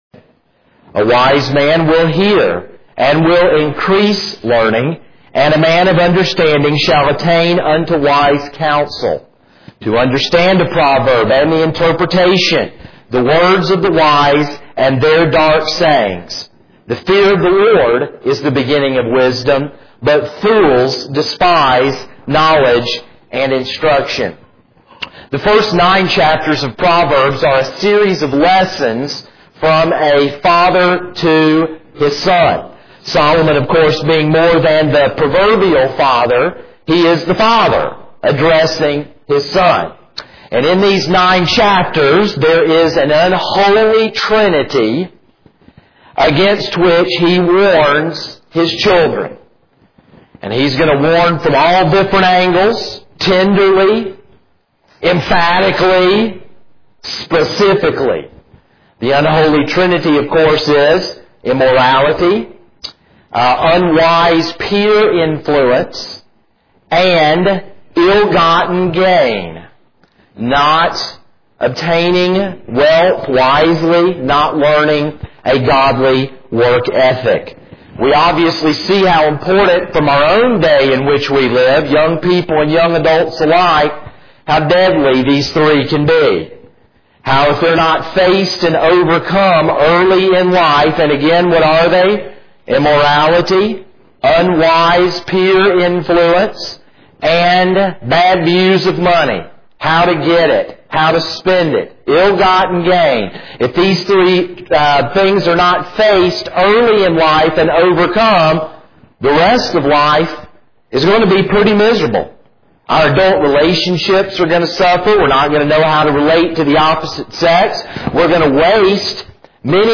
This is a sermon on Proverbs 1:1-7.